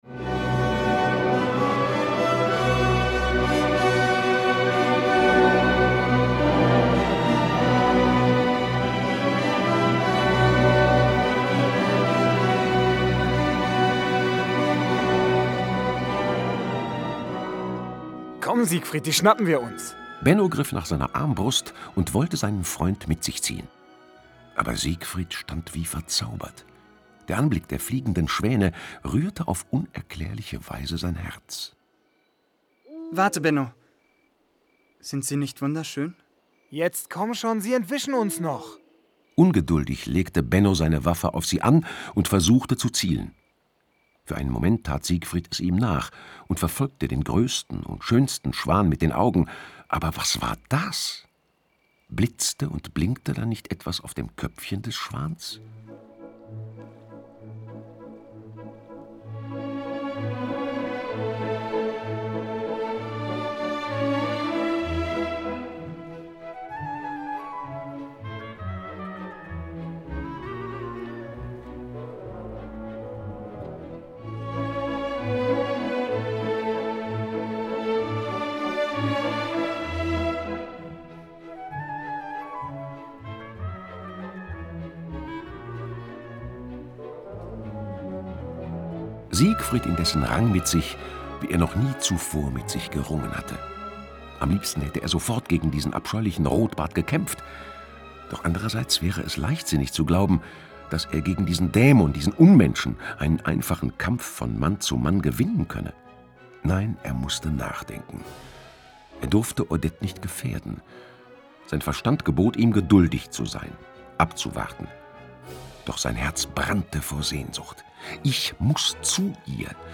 Hörspiel mit Musik